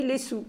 Localisation Saint-Maixent-de-Beugné
Catégorie Locution